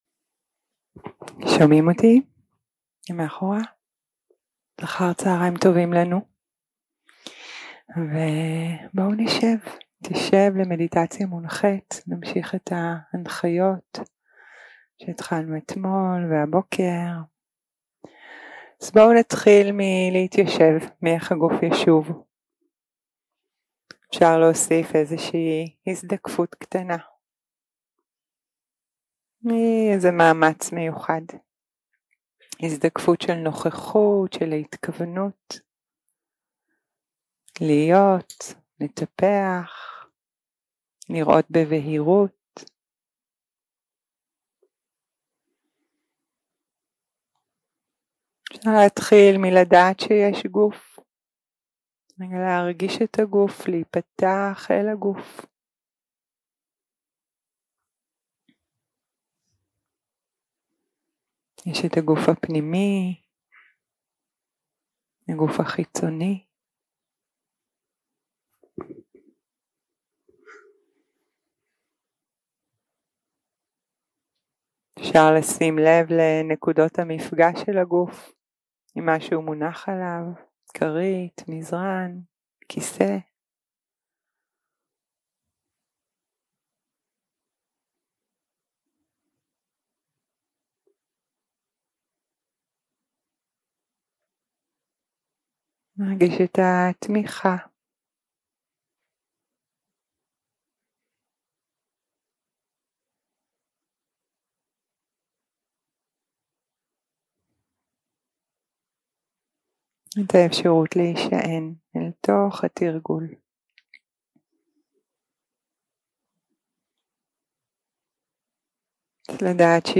יום 2 – הקלטה 3 – צהרים – מדיטציה מונחית